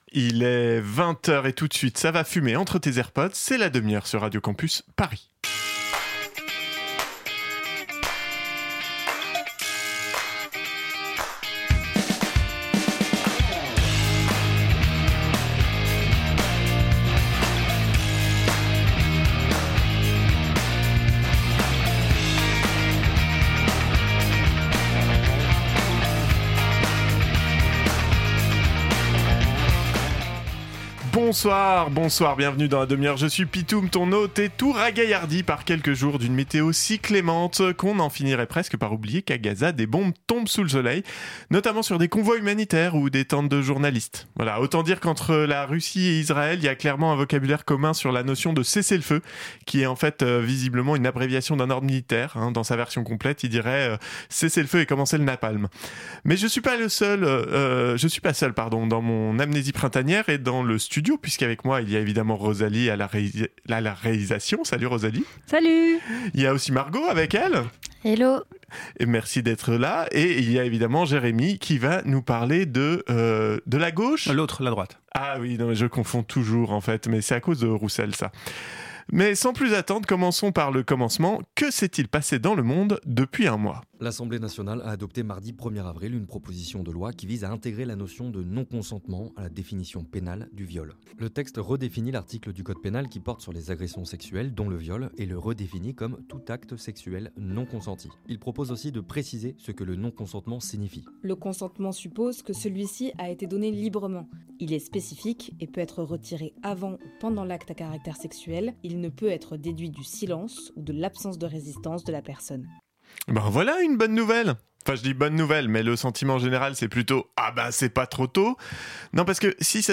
Partager Type Magazine Société mardi 8 avril 2025 Lire Pause Télécharger Ce mois-ci